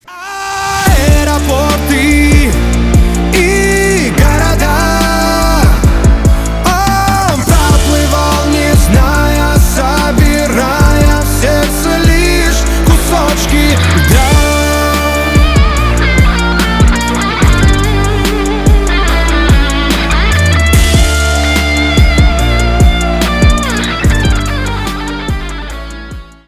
Рок Металл
громкие # кавер